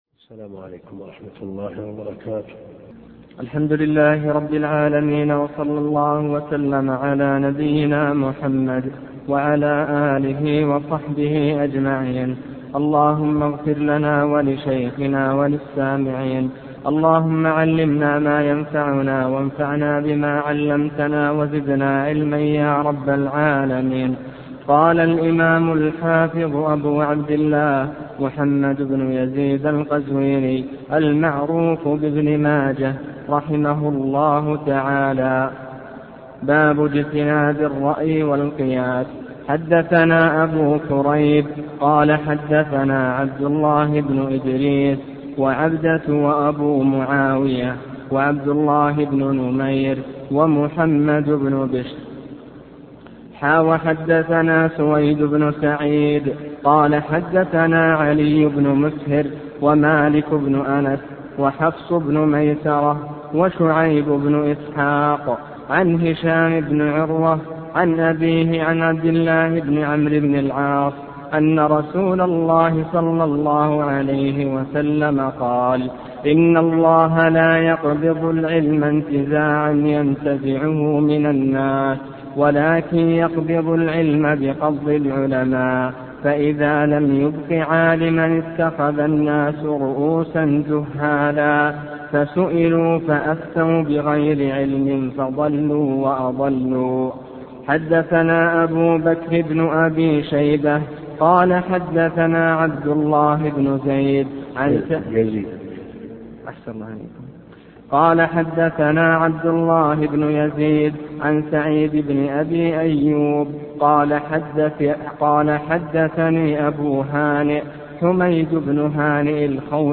الدرس (7) شرح سنن ابن ماجه - الدكتور عبد الكريم الخضير